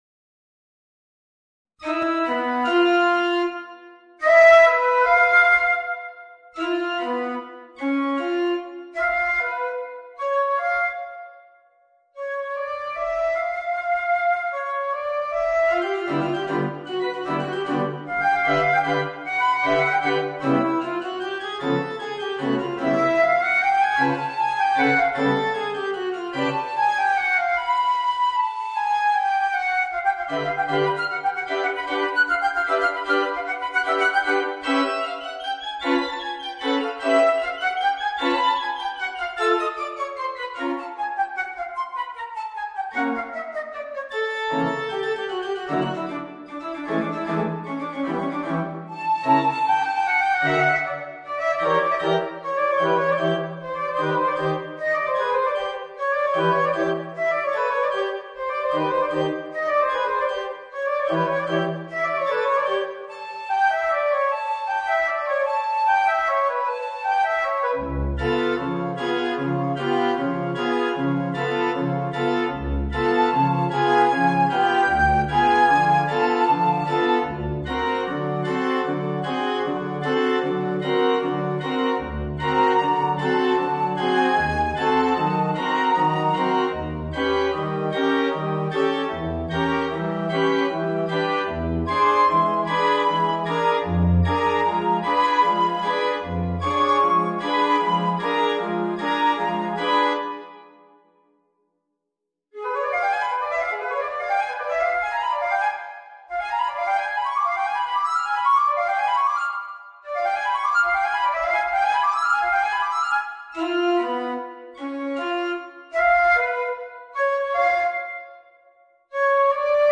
フルート+ピアノ